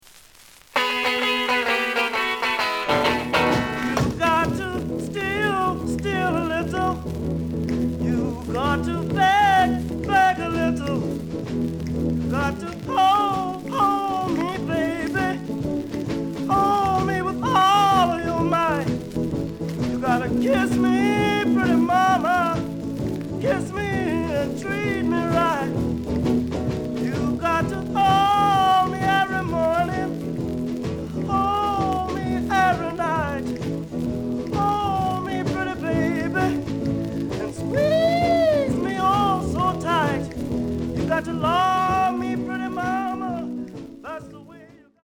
The listen sample is recorded from the actual item.
●Genre: Rhythm And Blues / Rock 'n' Roll
Slight noise on both sides.)